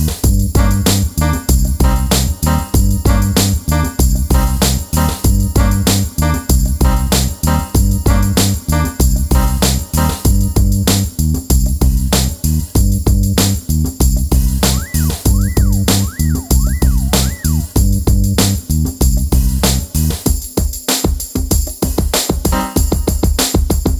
For Solo Female Duets 3:59 Buy £1.50